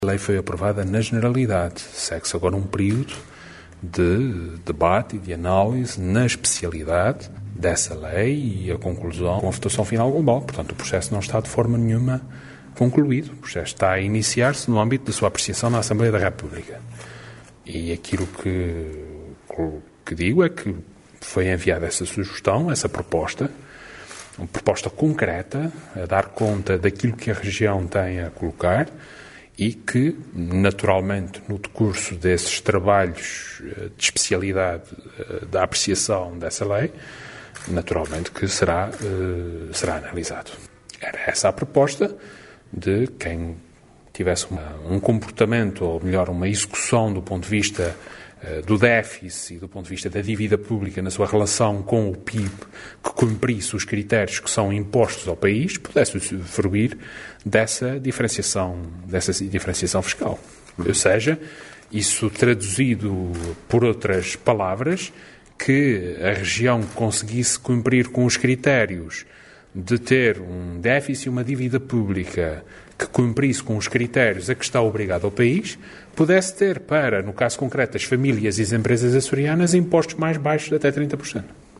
O presidente do Governo dos Açores, que falava aos jornalistas em Angra do Heroísmo, à margem da reunião do Conselho de Governo, recordou que a Região enviou ao Governo da República “uma proposta concreta” relativamente à revisão da Lei das Finanças das Regiões Autónomas, frisando que essa proposta “será analisada” na comissão parlamentar especializada.